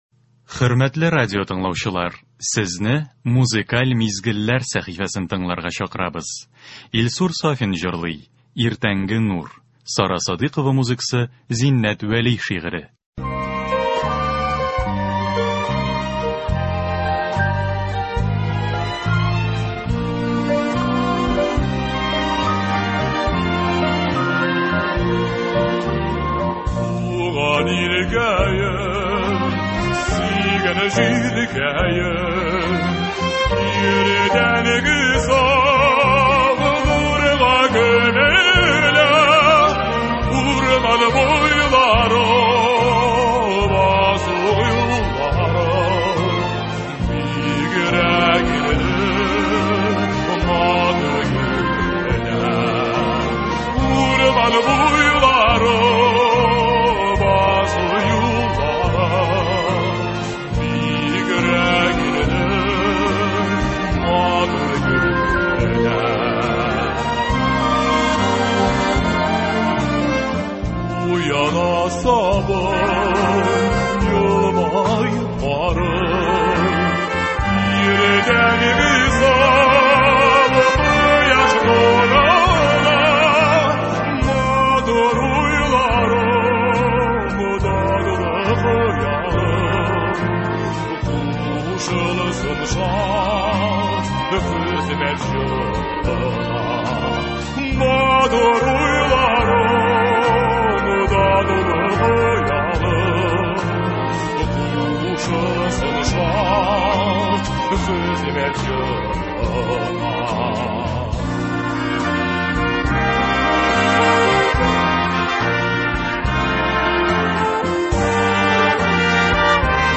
Музыкаль мизгелләр – һәр эш көнендә иртән безнең эфирда республикабыз композиторларының иң яхшы әсәрләре, халкыбызның яраткан җырлары яңгырый.